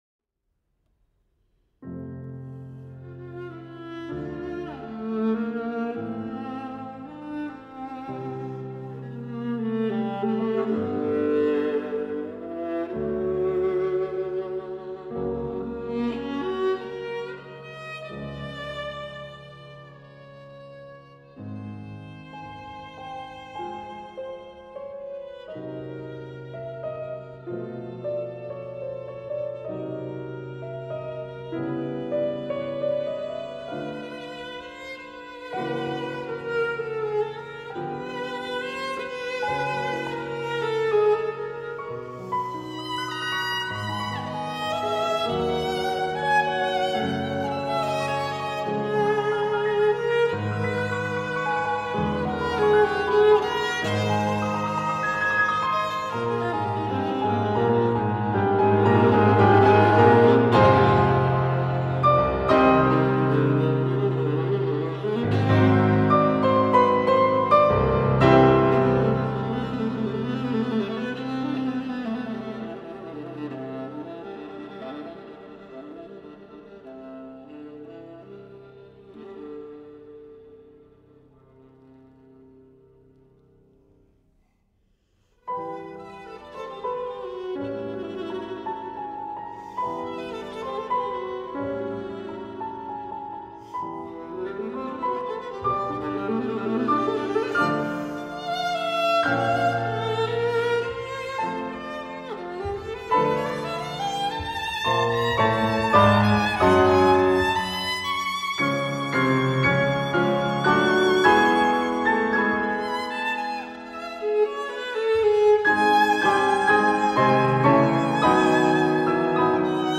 Paul-Hindemith-Sonata-For-Viola-And-Piano-Op.-11-Nr.-4-In-F-Major_-I.-Fantasie.mp3